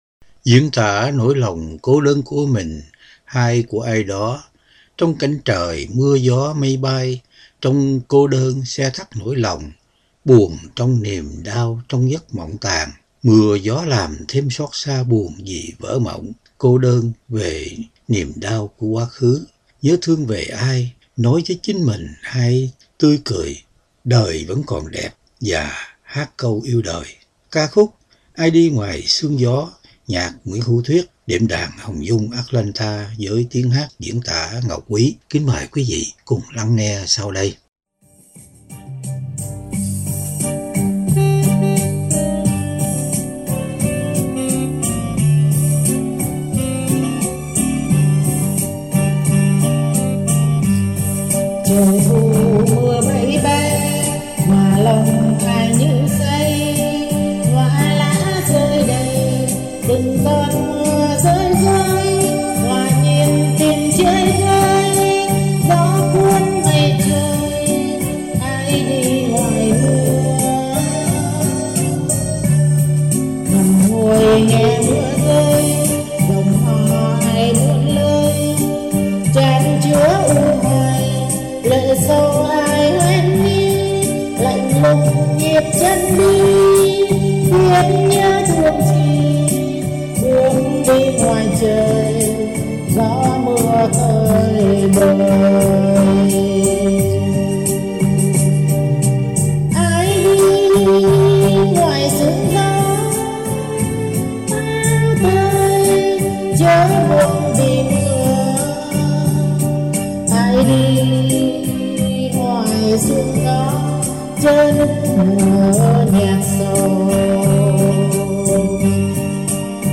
Đệm Đàn